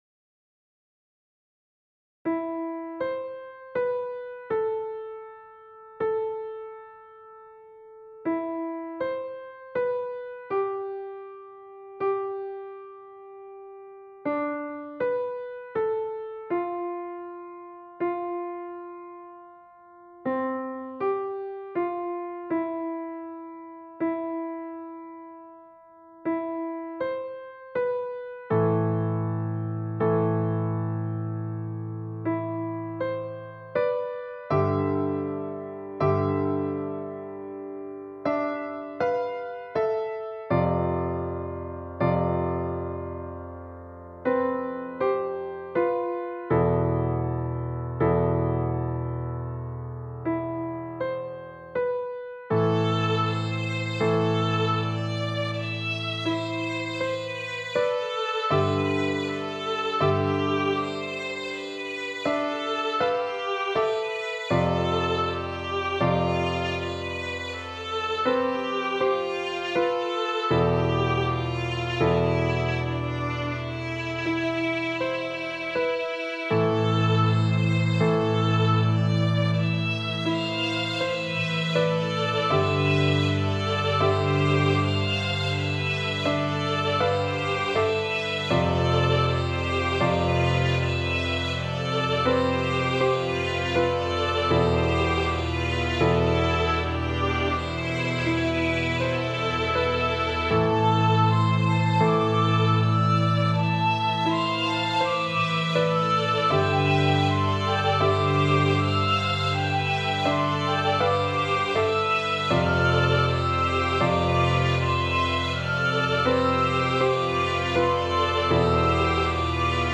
An Emotional Song
posted 5 months ago Storyteller and Future Novelist My most powerful, emotionally devastating song to date. This song is meant to bring out the negativity inside of you, to make you feel the feeling of hopelessness and loss.
Enjoy this wonderfully, dark song!
Music / Classical
emotional sad dark depressing despair hopeless devastating